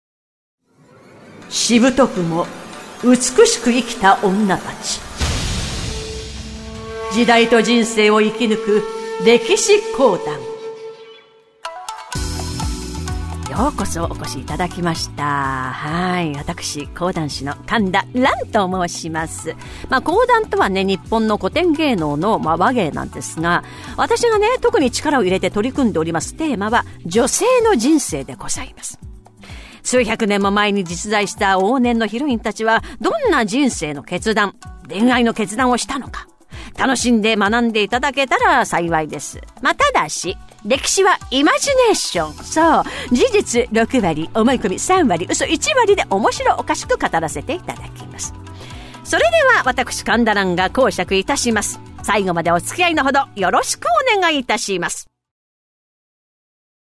講談とはね、日本の古典芸能の話芸なんですが、私が特に力を入れて取り組んでおりますテーマは「女性の人生」でございます。